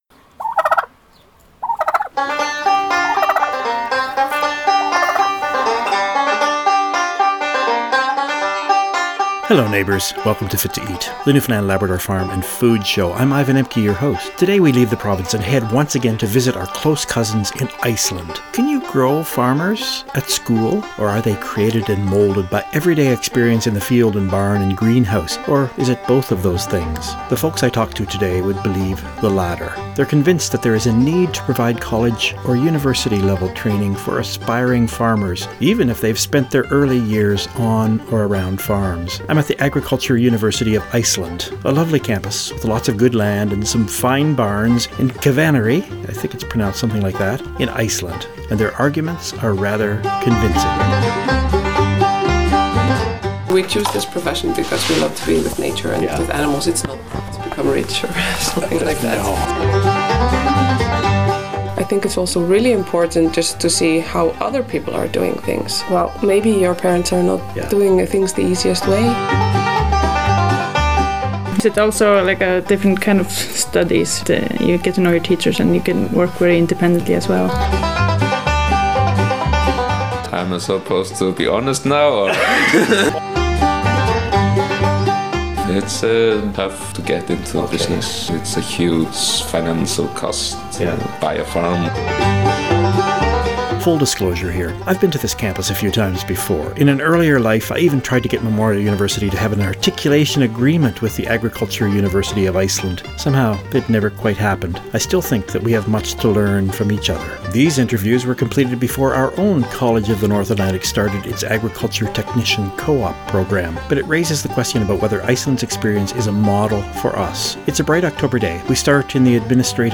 At the Agriculture University of Iceland